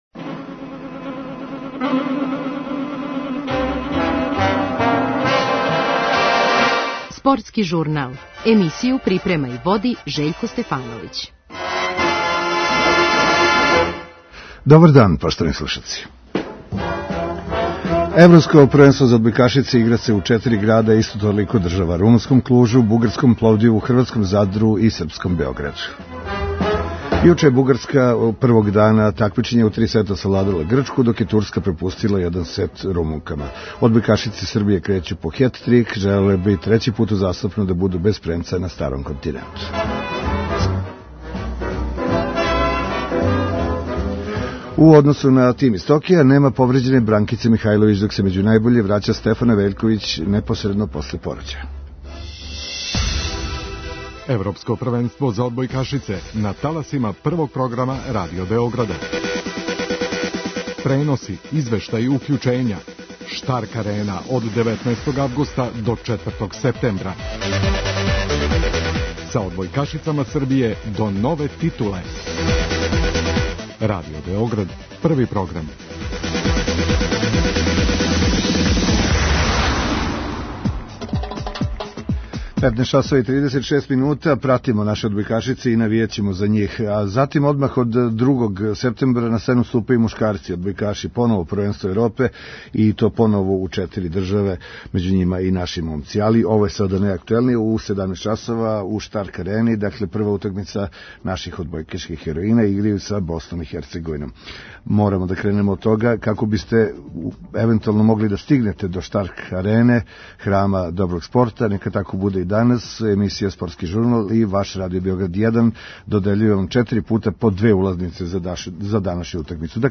Уочи првог сусрета на ЕП, чућемо селектора Зорана Терзића, капитена Мају Огњеновић и смечерку Јелену Благојевић. преузми : 4.48 MB Спортски журнал Autor: Спортска редакција Радио Београда 1 Слушајте данас оно о чему ћете читати у сутрашњим новинама!